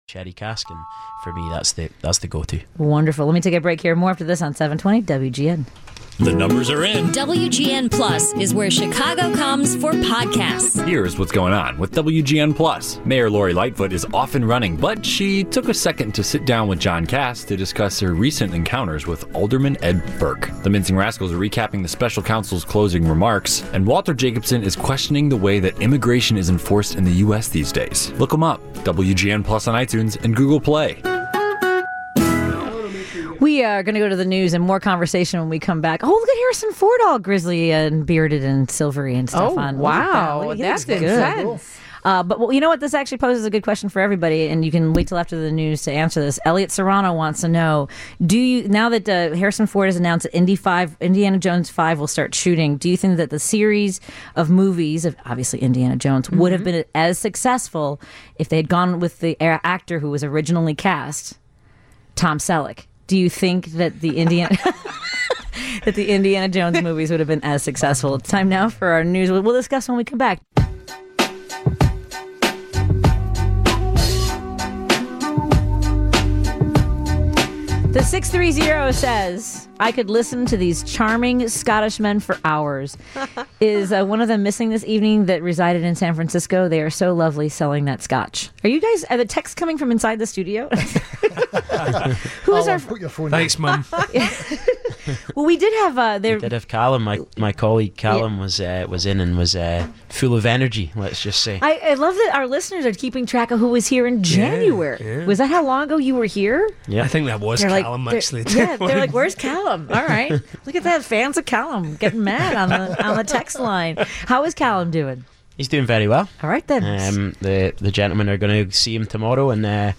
joins us in our Allstate Skyline Studio